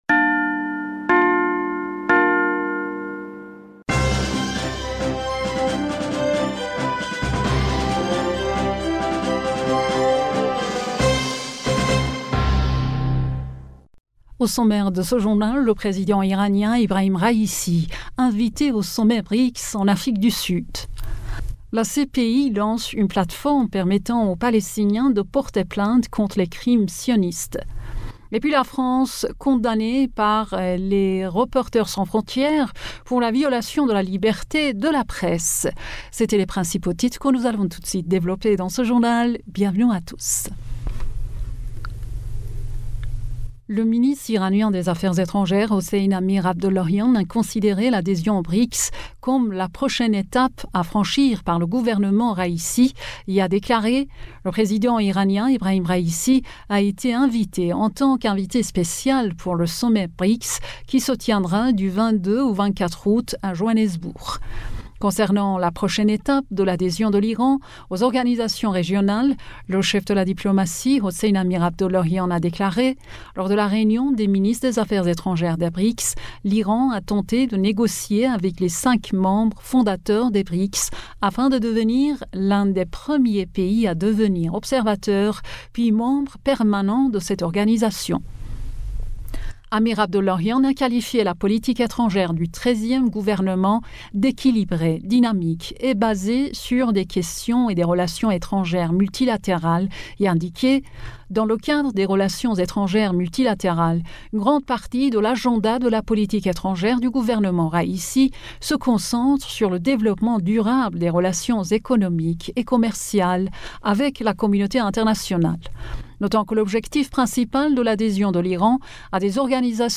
Bulletin d'information du 19 Juillet 2023